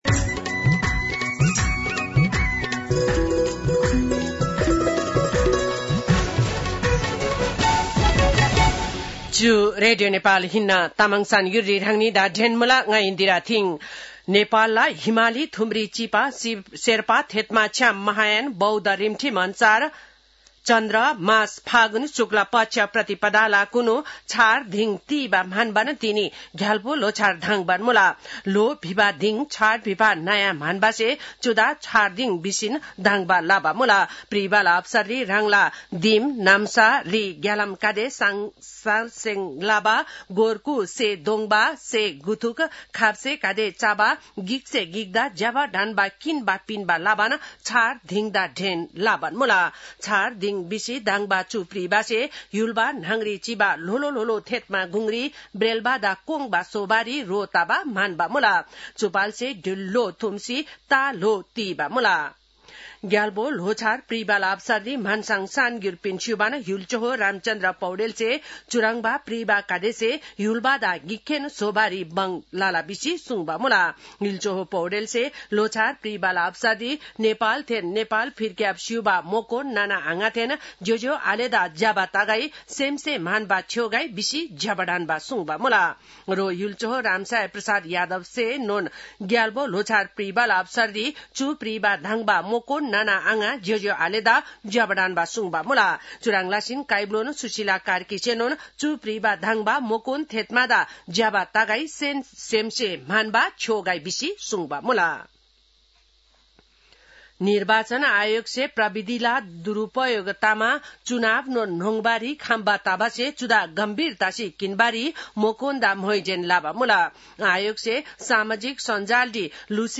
तामाङ भाषाको समाचार : ६ फागुन , २०८२